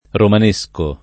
romanesco [ roman %S ko ]